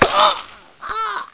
Here are some ripped soundFX from TR2 using Cool Edit Pro.